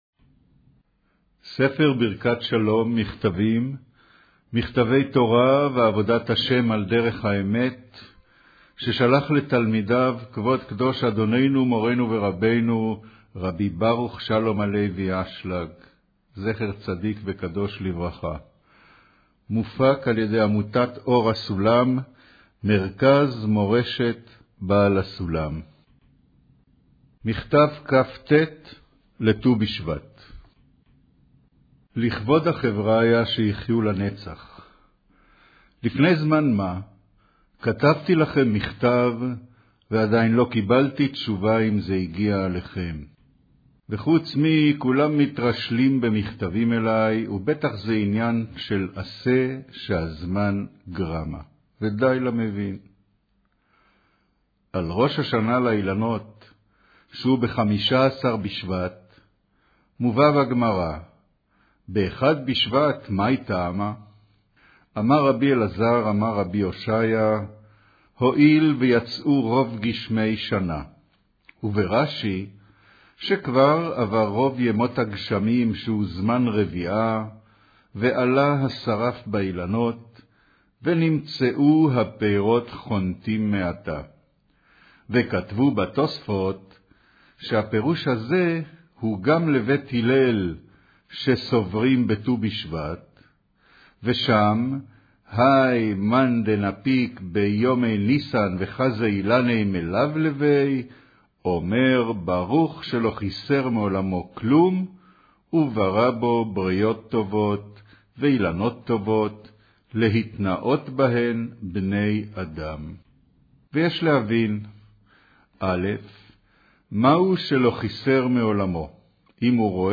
אודיו - קריינות אגרת כט'